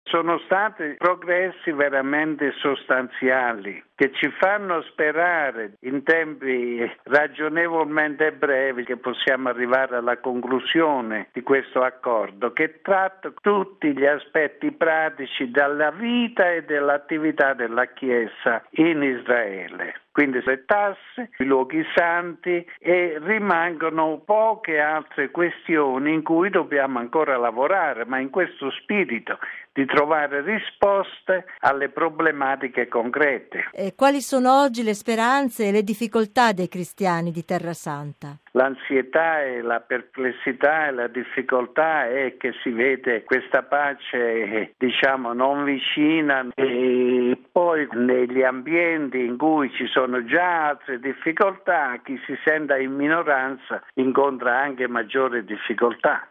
ha intervistato mons. Antonio Franco, nunzio apostolico in Israele delegato apostolico di Gerusalemme e Territori palestinesi